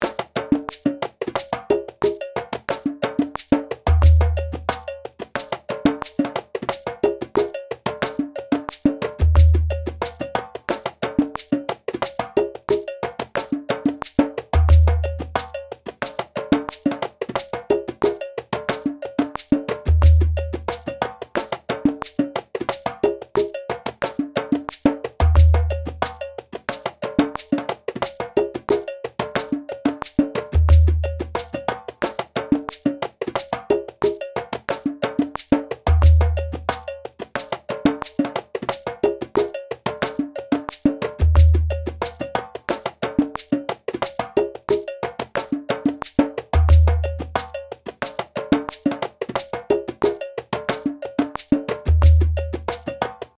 base lezione 35 – 90bpm Download
base-lez-35-90bpm.mp3